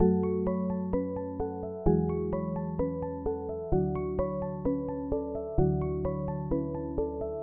一个有趣的吉他旋律1
标签： 129 bpm Trap Loops Guitar Acoustic Loops 1.25 MB wav Key : Unknown FL Studio
声道立体声